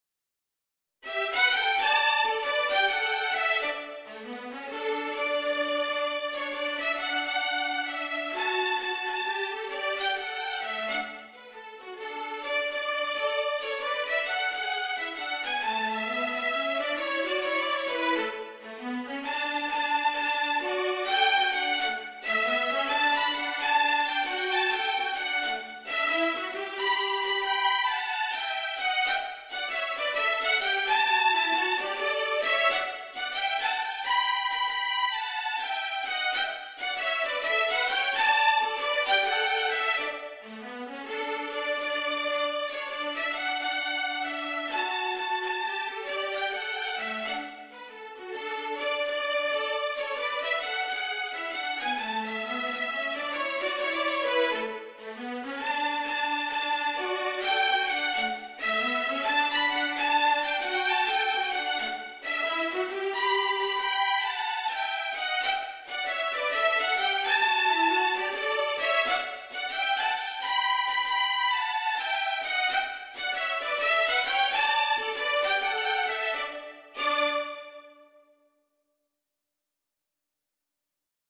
An upbeat swing version of
Jazz and Blues